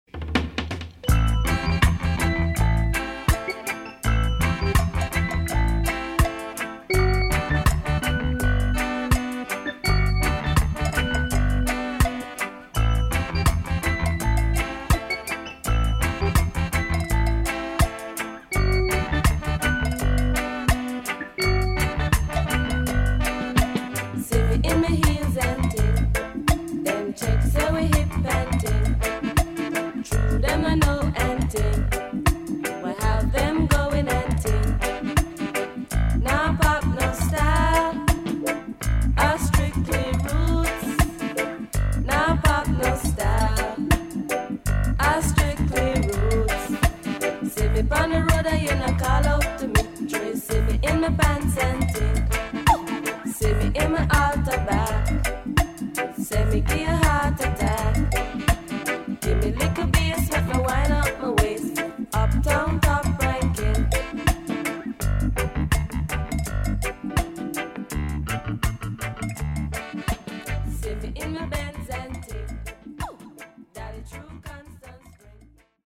前述の名曲とは打って変わり、いい意味で期待を裏切るマイナー調の良曲多数収録です。